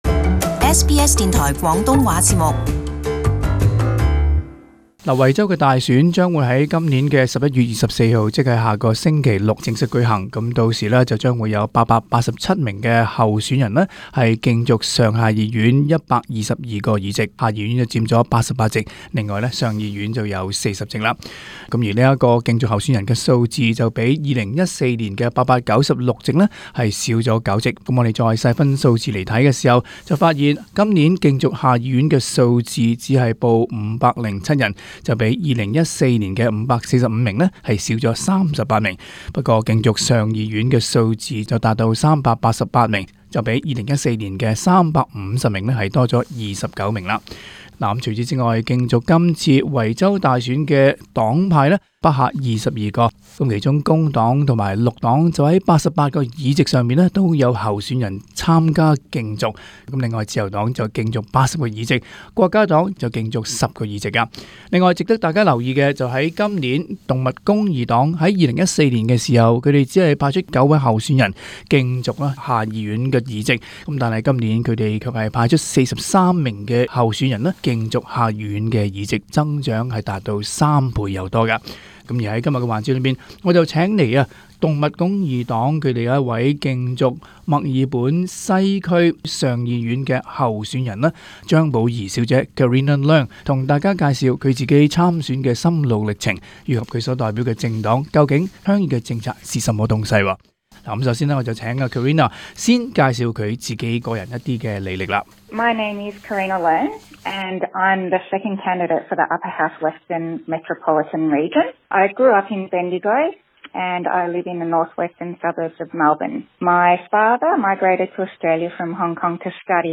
【社團專訪】